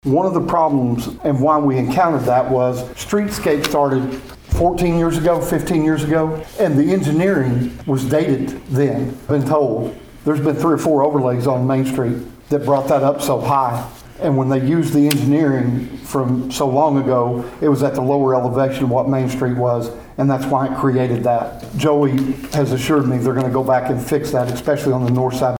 During Tuesday evening's city council meeting in Pawhuska, City Manager Jerry Eubanks talked about the streetscape project and why cars have had to put up with a speedbump